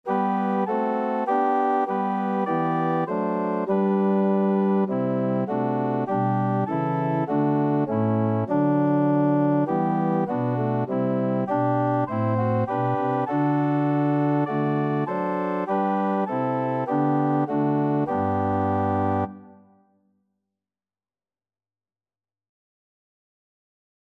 4/4 (View more 4/4 Music)
Organ  (View more Intermediate Organ Music)
Christian (View more Christian Organ Music)